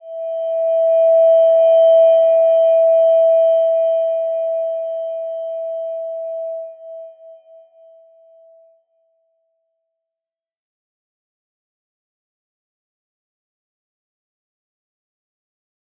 Slow-Distant-Chime-E5-f.wav